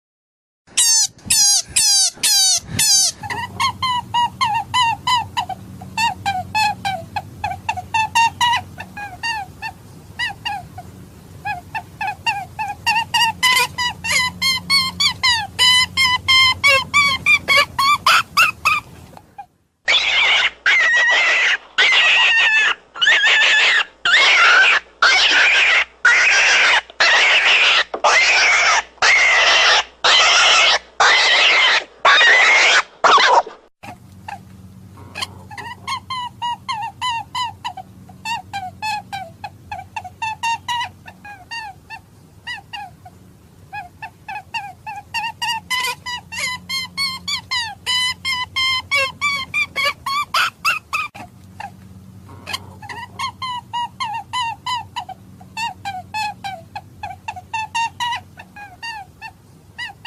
Tiếng Thỏ rừng kêu mp3